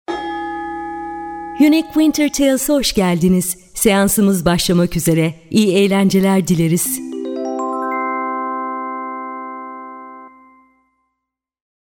Versatile and expressive voice-over artist with a background in radio, music, and storytelling.
Clear diction, emotional range, and a warm, engaging tone – available in both English and Turkish.
Sprechprobe: Industrie (Muttersprache):